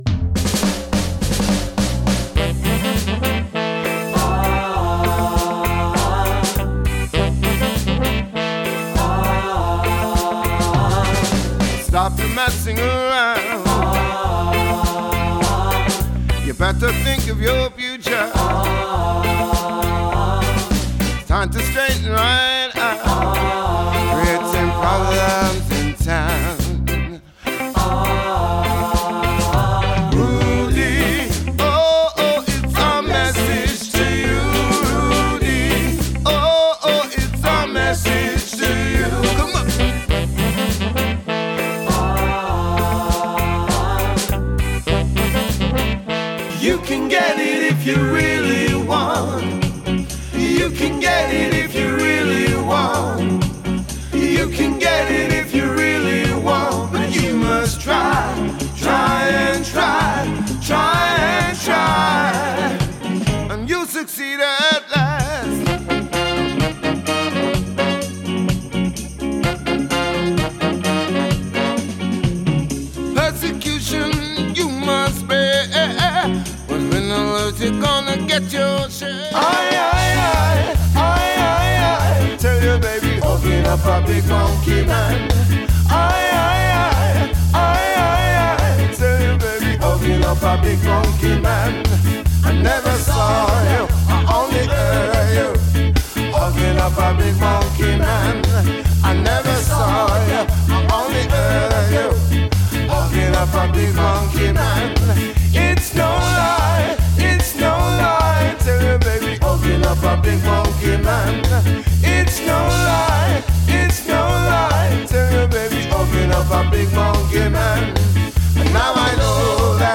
Fantastic Ska Band
horn section
male and female vocalists that take it in turns singing lead